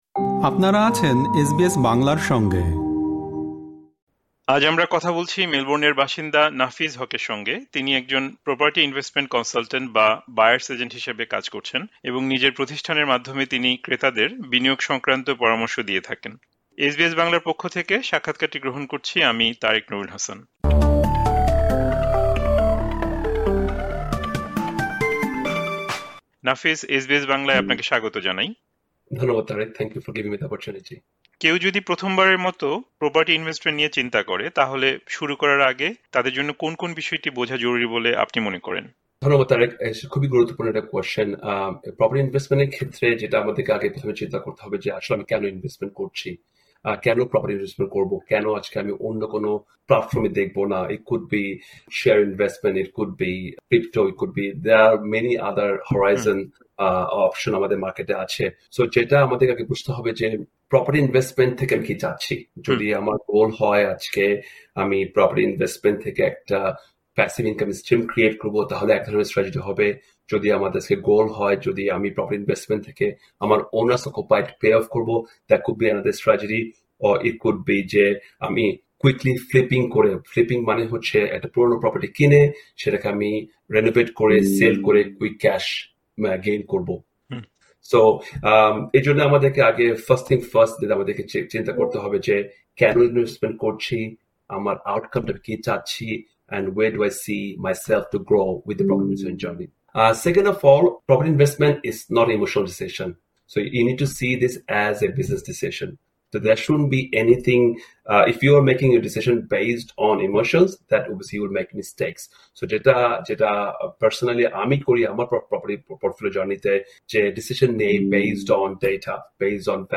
এই সাক্ষাৎকারে আলোচনায় এসেছে—একজন নতুন বিনিয়োগকারী কীভাবে বাজার বিশ্লেষণ করবেন, কোন কোন জায়গাকে ভবিষ্যতের জন্য সম্ভাবনাময় হিসেবে বিবেচনা করা যায়, এবং একটি প্রপার্টি কেনার আগে কোন বিষয়গুলো যাচাই করা জরুরি।